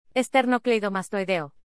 Here’s how to pronounce this word: